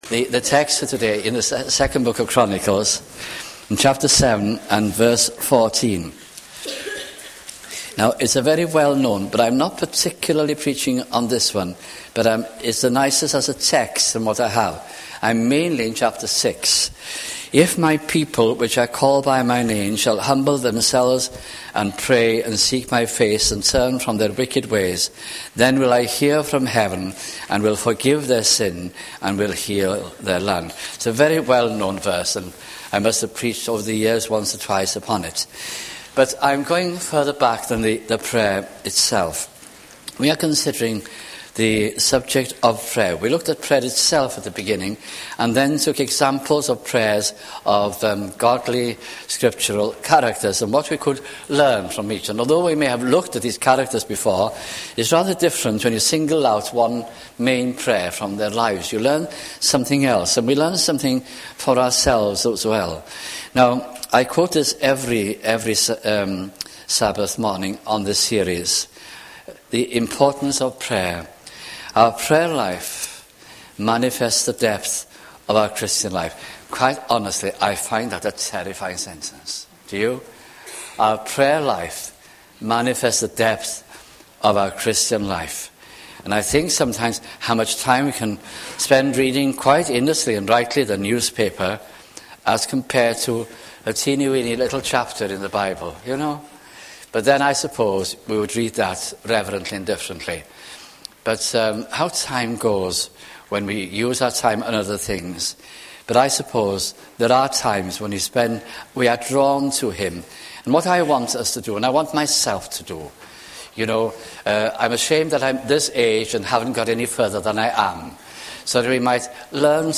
» Prayer 1999 » sunday morning messages